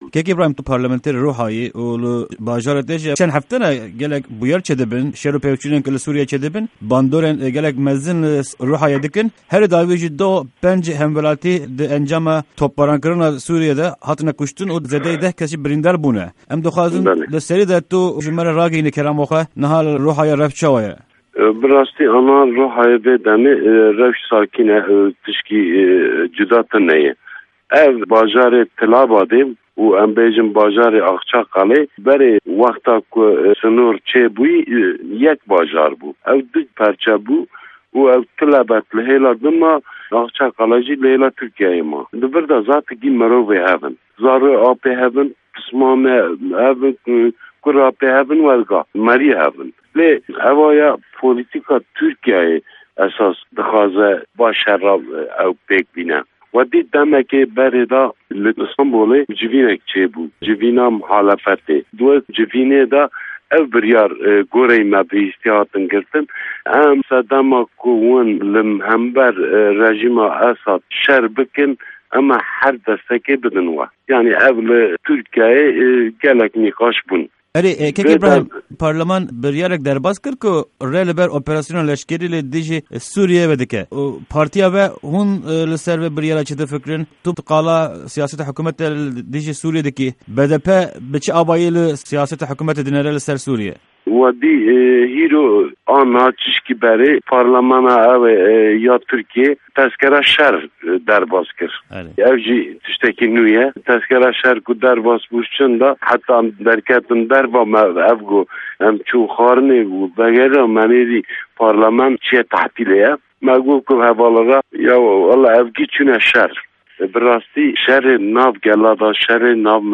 Parlamenterê Partîya Aştî û Demokrasîyê (BDP) yê bajarê Ruhayê Îbrahîm Bînîcî di hevpeyvîneke Pişka Kurdî ya Dengê Amerîka de dibêje, biryara parlemana Tirkîyê ku rê dide operasyonên dijî Sûrîyê biryarake ne rast e.
Hevpeyvîn_Îbrahîm_Bînîcî